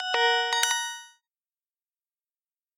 На этой странице собраны звуки электронной почты: уведомления о новых письмах, отправке сообщений и другие сигналы почтовых сервисов.
Звук входящего письма для Мейл ру или Яндекс почты